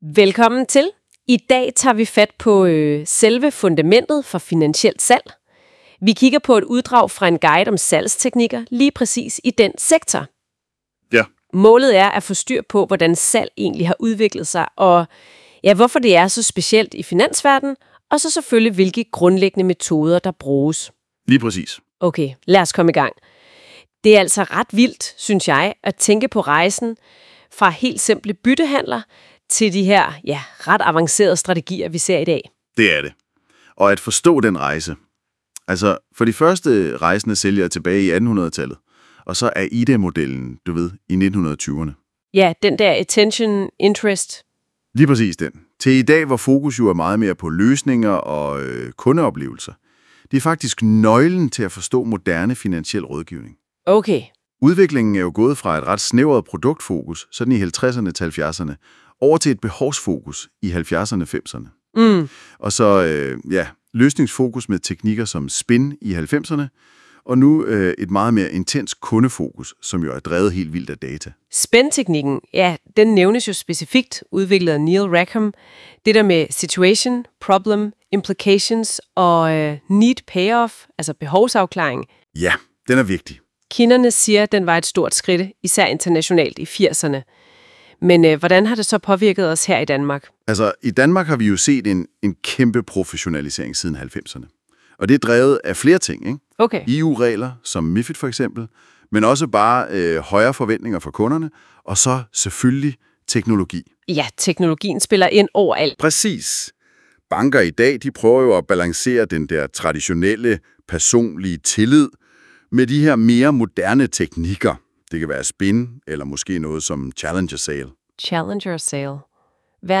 Podcast om salgstilgange i finanssektoren